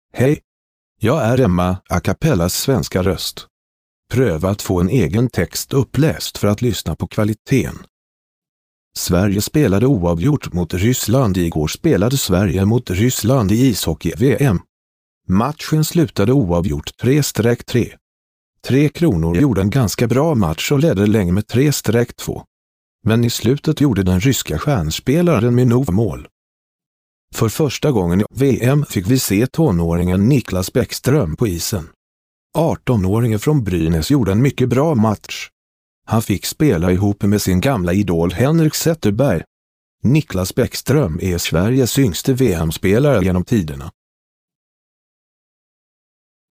Texte de d�monstration lu par Erik (Acapela High Quality Text To Speech Voices; distribu� sur le site de Nextup Technology; homme; su�dois)